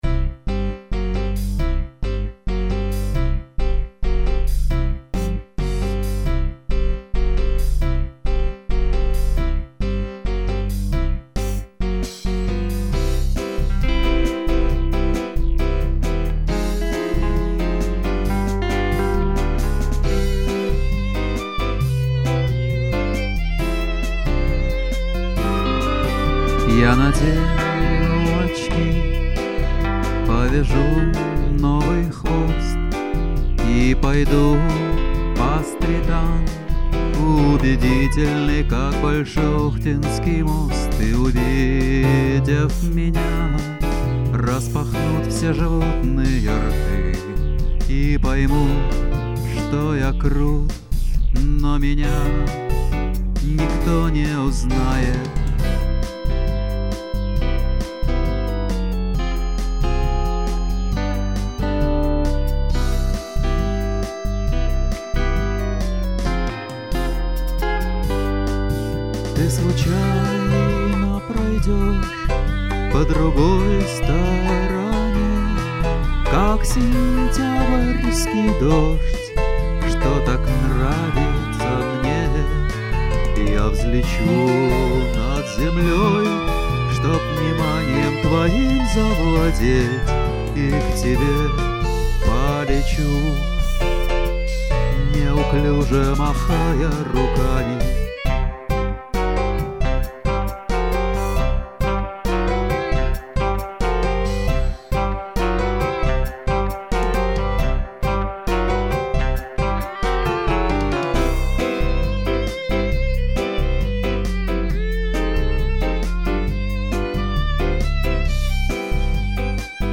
гитара, пение, бытовые шмудаки